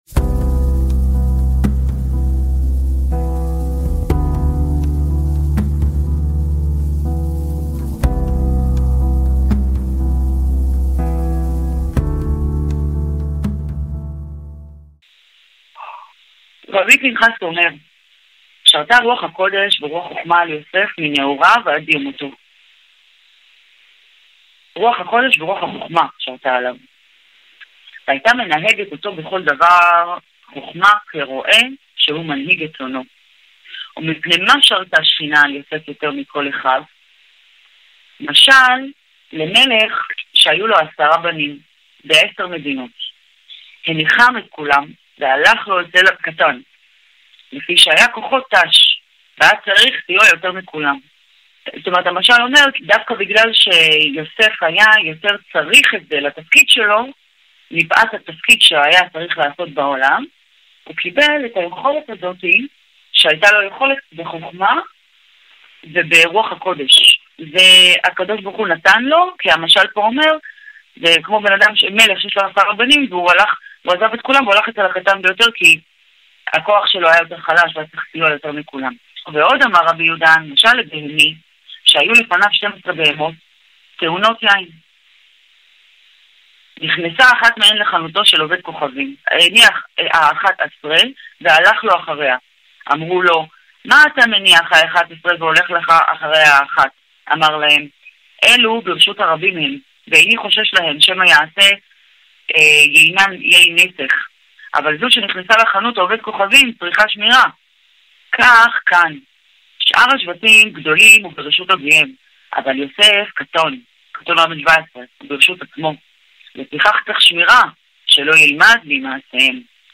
מתוך שיעורים בקבוצת החברותא, מתוך ספר אבותינו (הרב אהרון וקסלרשטיין) עמודים קפ עד קפה.